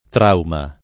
trauma [ tr # uma ] s. m.; pl. ‑mi